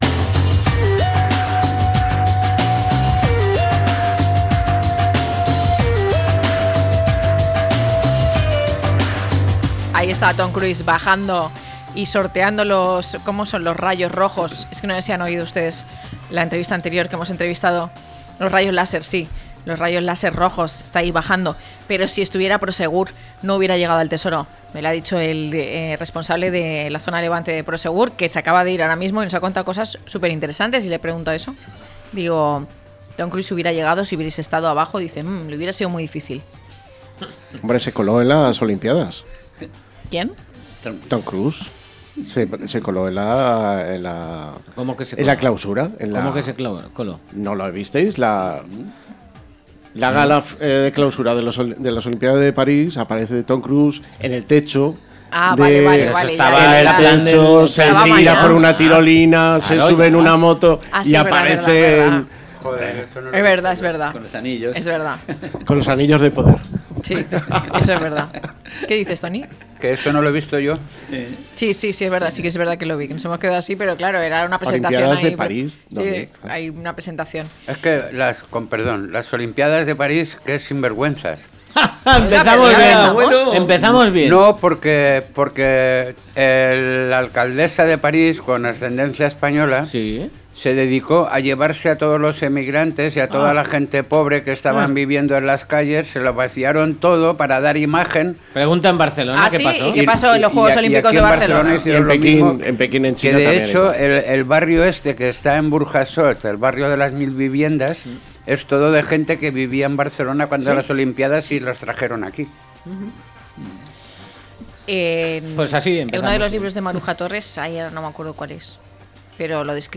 Despedido por la empresa cuando estaba en coma, a debate - La tarde con Marina